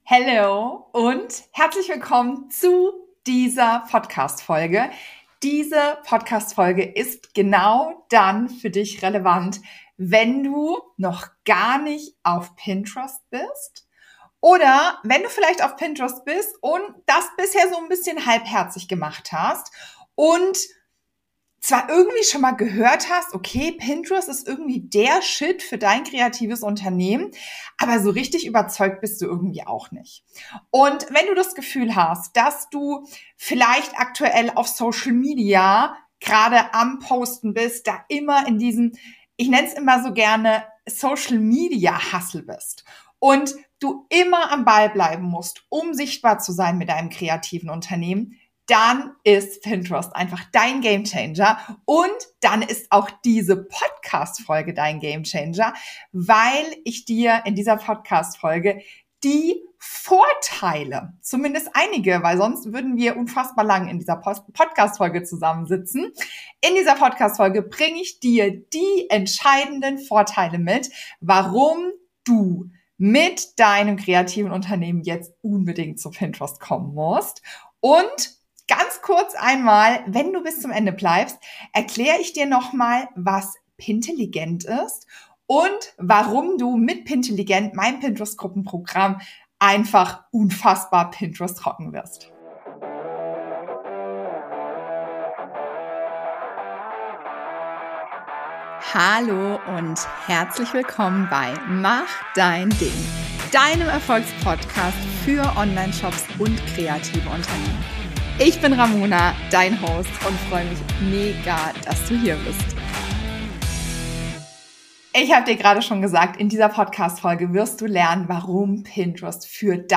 Warum ohne Branding kein WOW kommen kann - Interview